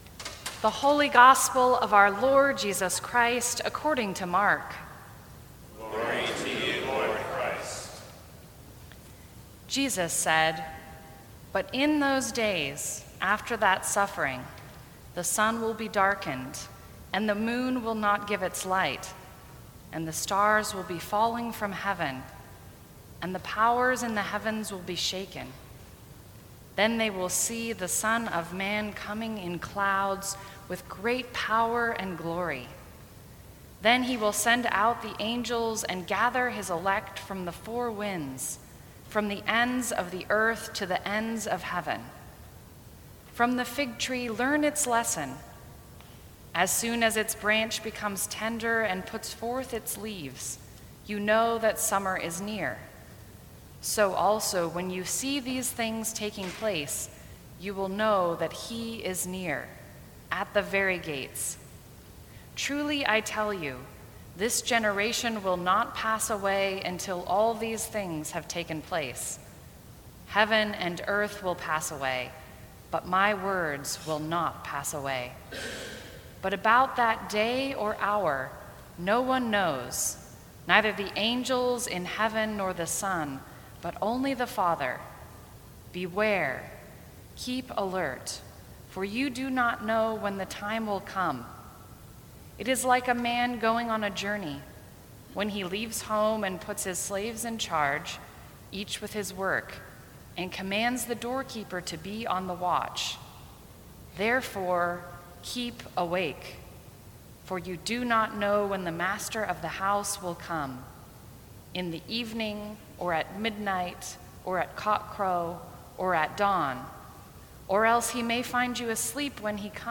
Sermons from St. Cross Episcopal Church Be Awake Dec 04 2017 | 00:13:32 1x 00:00 / 00:13:32 Subscribe Share Apple Podcasts Spotify Overcast RSS Feed Share Link Embed